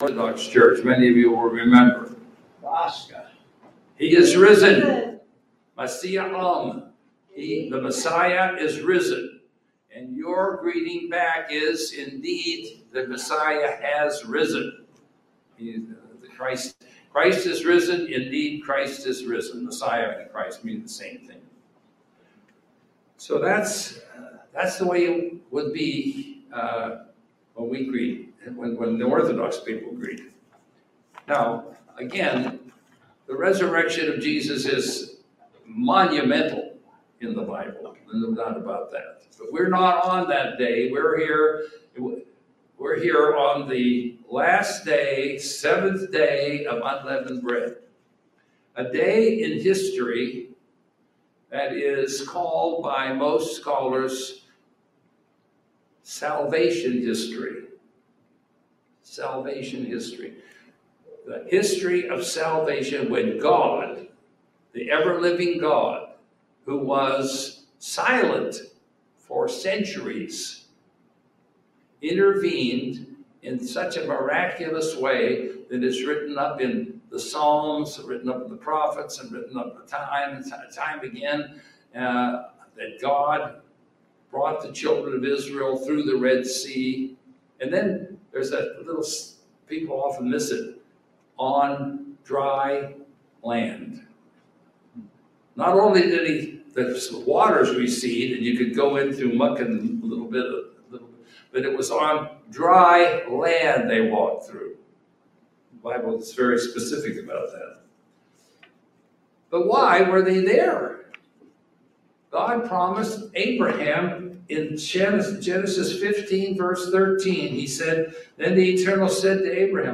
Latest Sermon